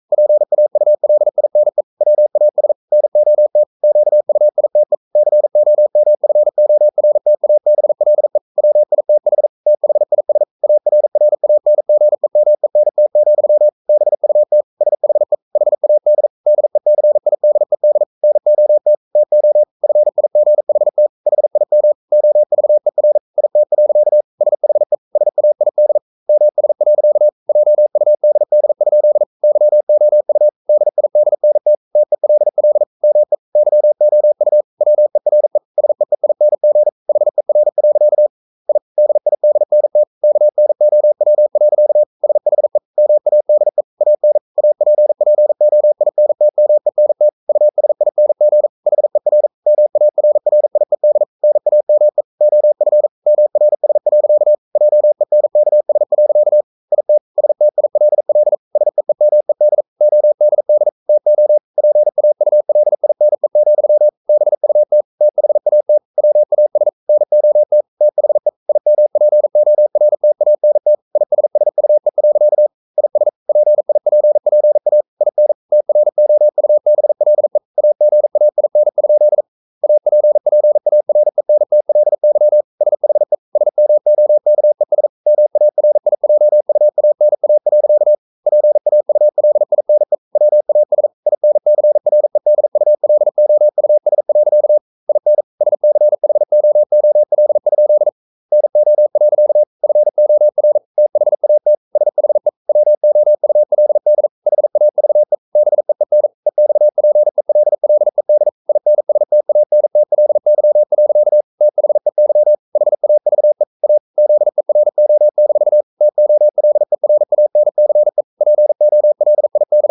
Never 42wpm | CW med Gnister
Never_0042wpm.mp3